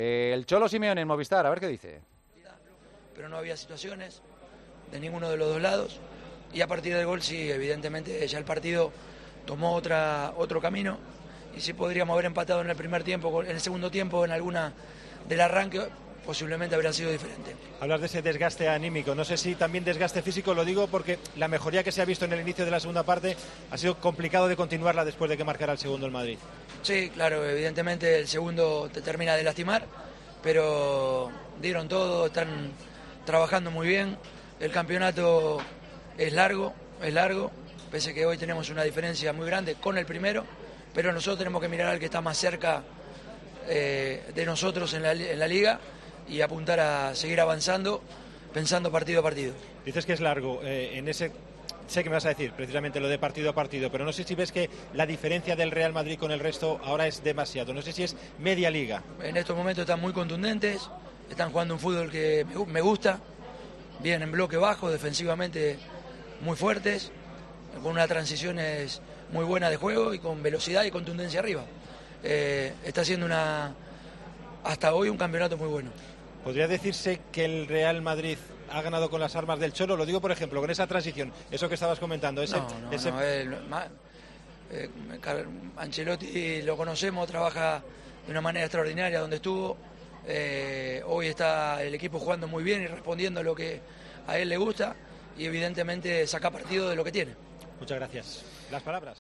Ganaron justamente", afirmó en rueda de prensa.